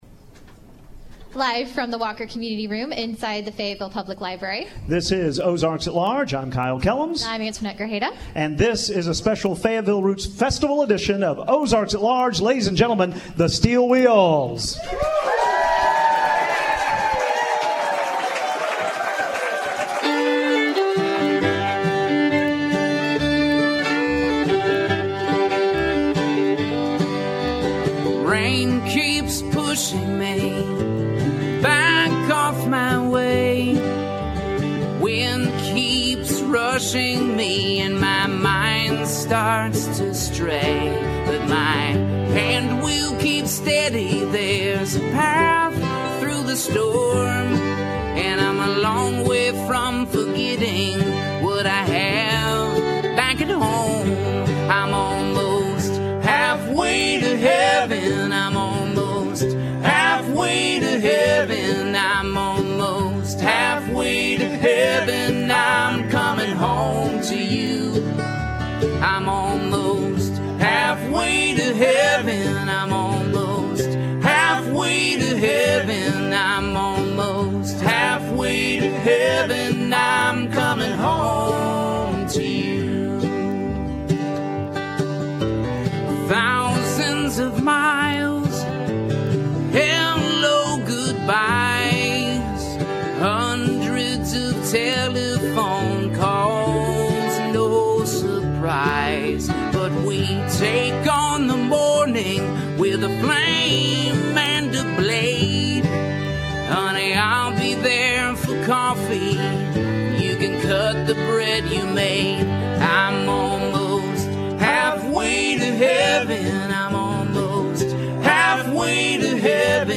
live roots reals edit.mp3